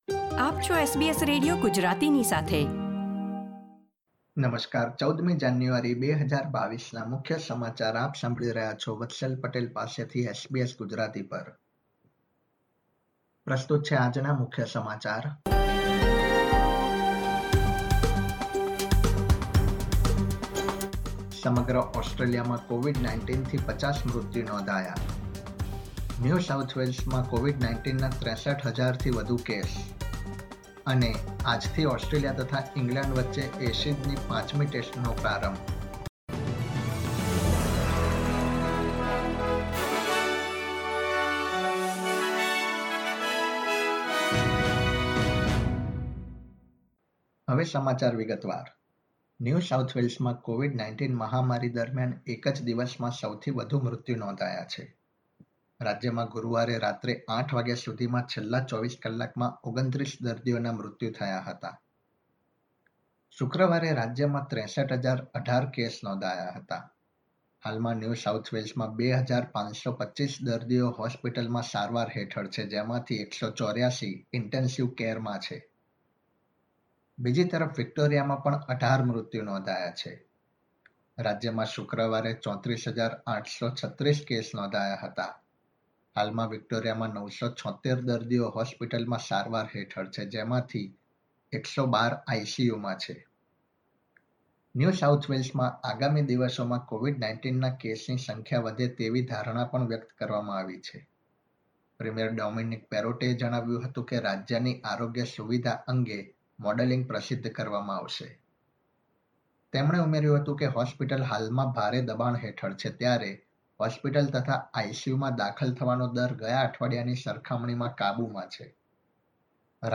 SBS Gujarati News Bulletin 14 January 2022